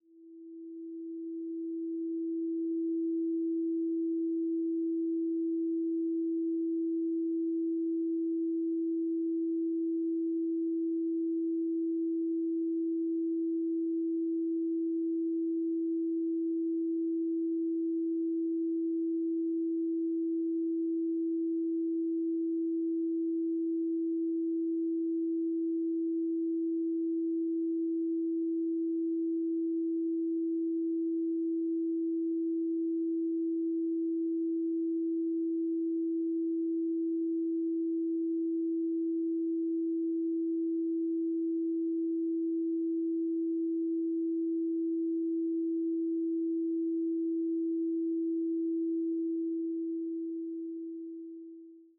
🎹 Classical Piano Collection
Beautiful piano pieces inspired by the great composers.
Duration: 0:52 · Genre: Romantic · 128kbps MP3